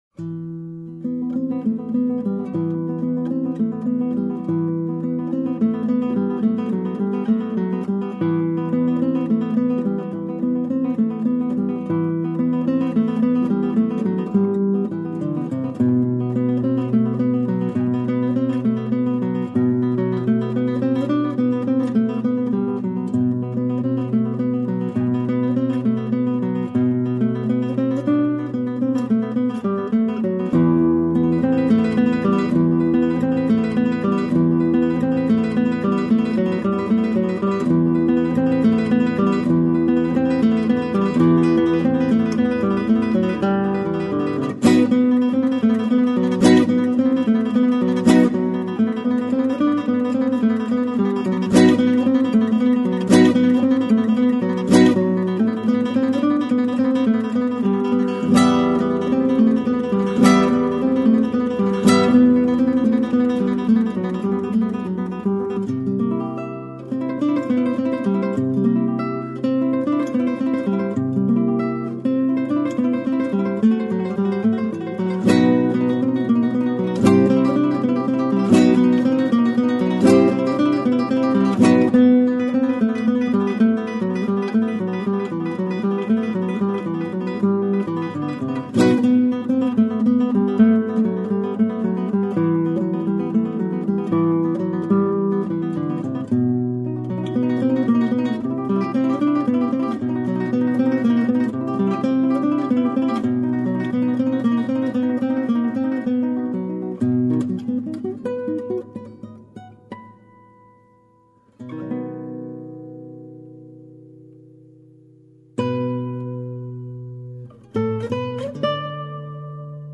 Indianapolis Guitarist 1